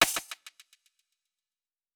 Beats Perc.wav